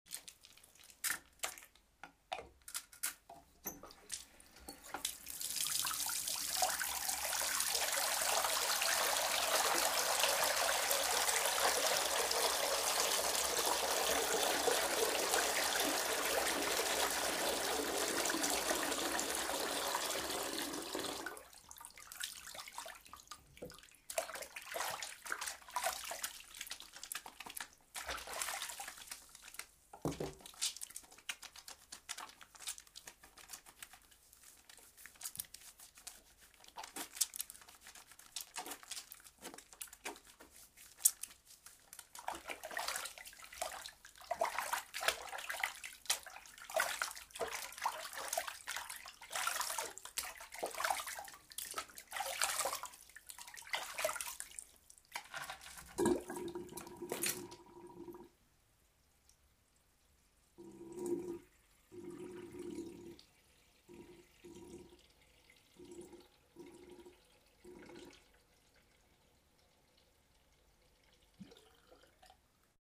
جمع المياه ، غسل اليد | تأثير الصوت. mp3 | حمل مجانا.
جمع المياه ، غسل اليد: